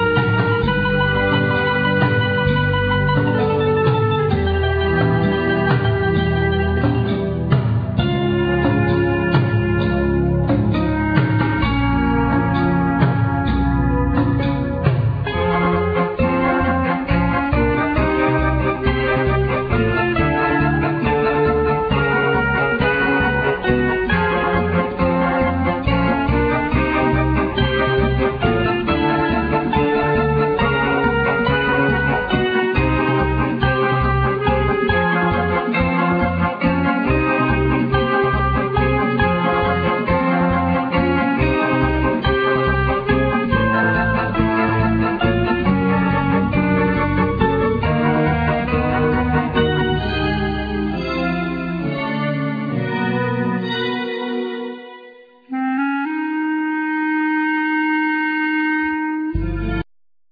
Clarinet
Keyboards,Short Waves
Cello
Percussions
Chapman Stick
Violin